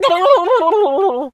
turkey_ostrich_hurt_gobble_08.wav